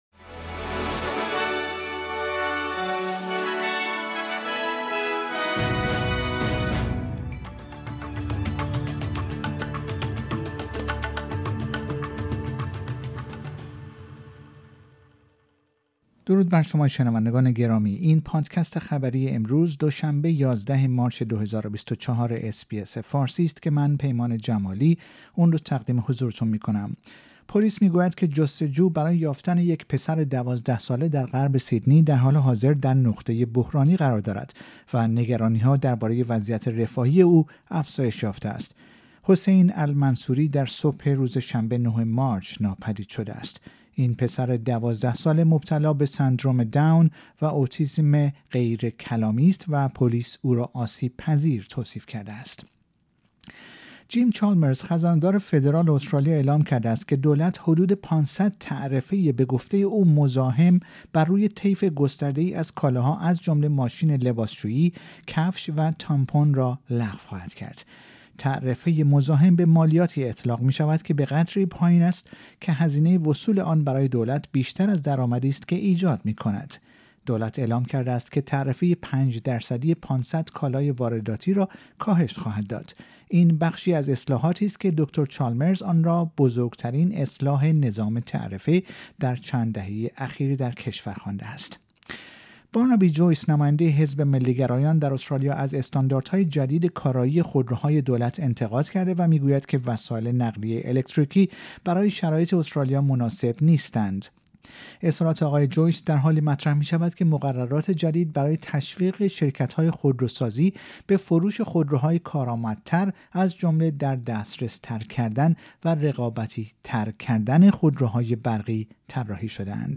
در این پادکست خبری مهمترین اخبار استرالیا و جهان در روز دوشنبه ۱۱ مارچ ۲۰۲۴ ارائه شده است.